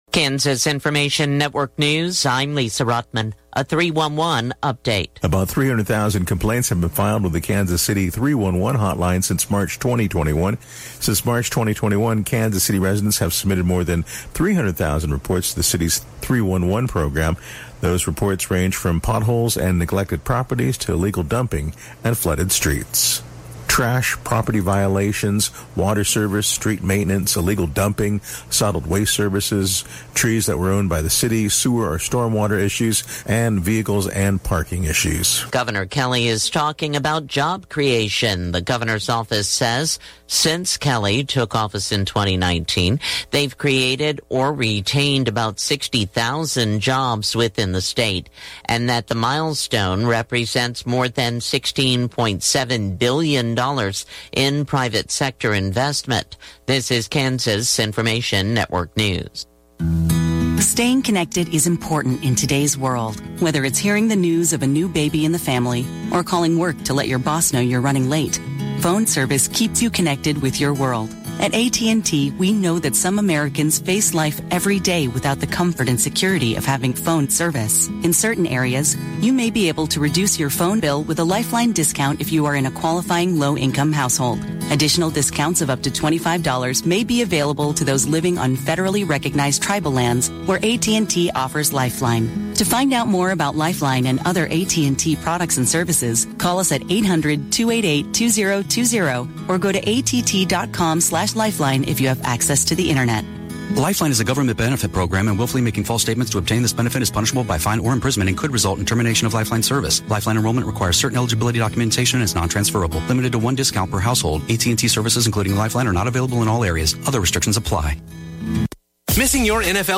Classic Hits KQNK News, Weather & Sports Update – 8/10/2023